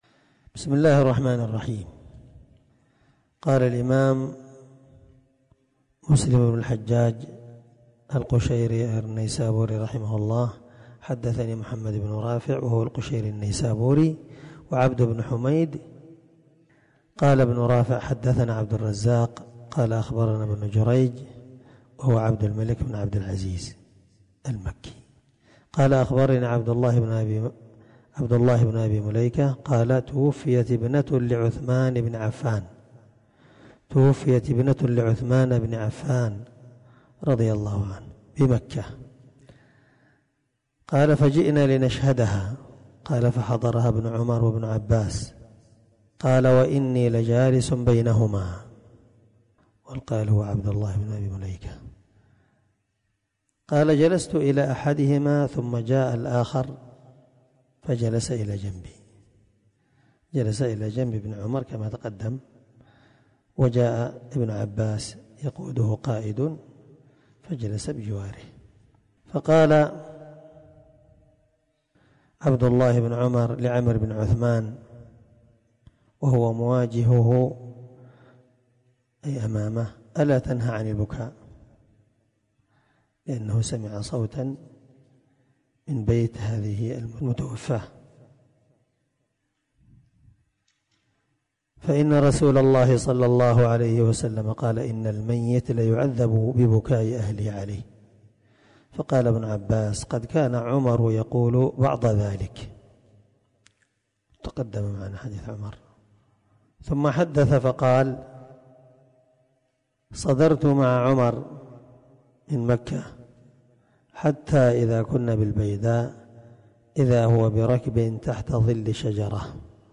565الدرس 10من شرح كتاب الجنائز حديث رقم(929-933) من صحيح مسلم
دار الحديث- المَحاوِلة- الصبيحة.